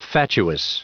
Prononciation du mot fatuous en anglais (fichier audio)
Prononciation du mot : fatuous